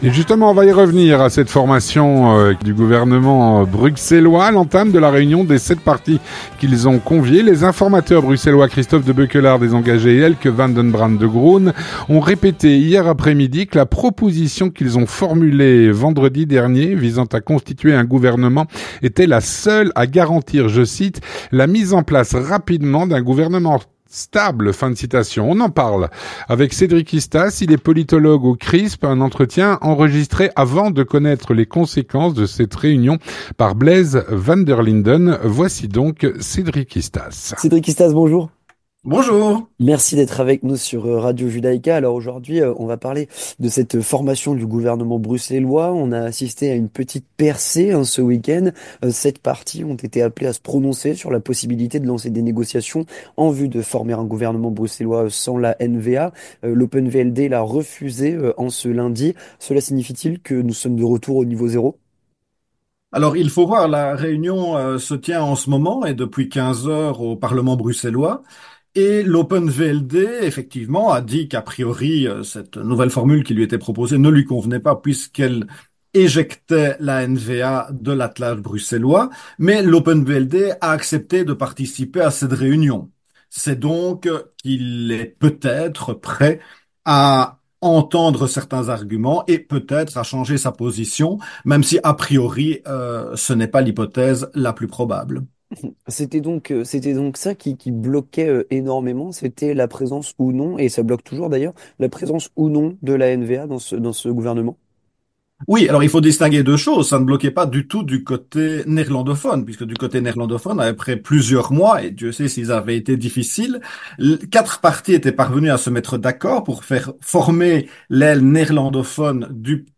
Un entretien enregistré avant de connaître les conséquences de cette réunion.